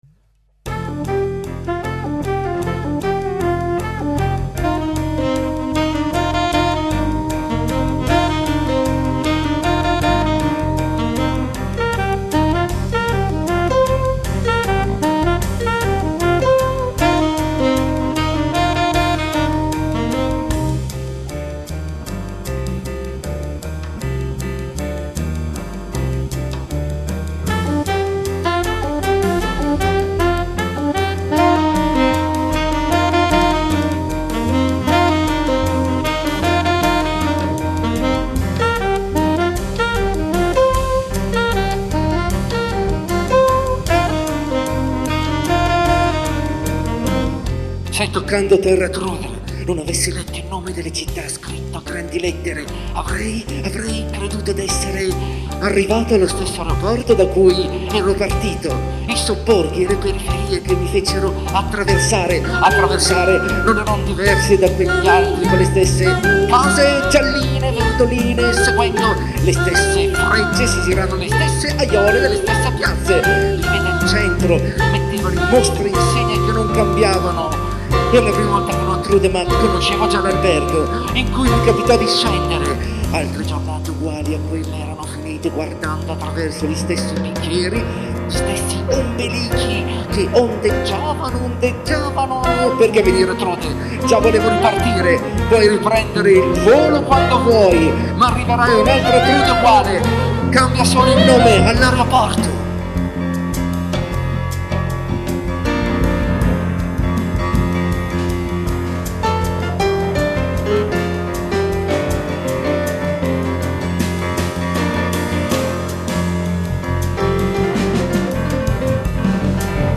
pianoforte
attore, live electronics
sax alto